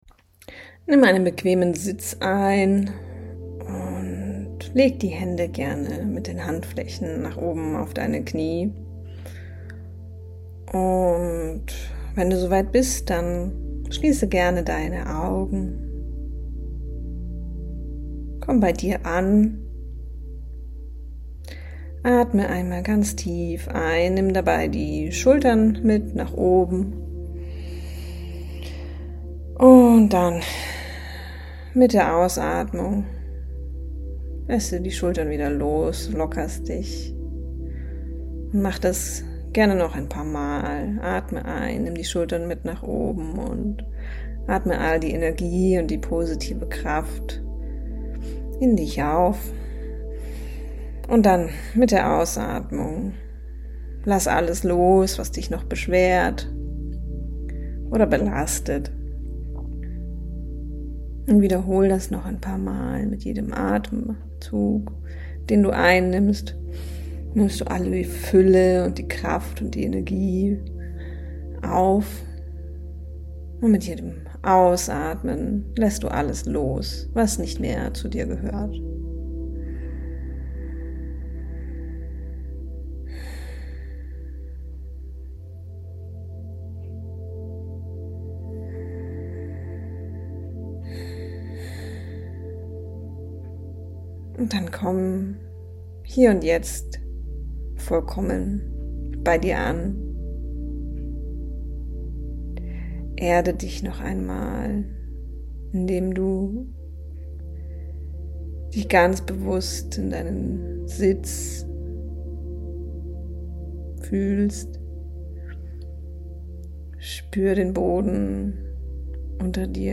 Diese 20-minütige geführte Meditation unterstützt dich dabei, deine nächsten Schritte zur finanziellen Fülle zu visualisieren.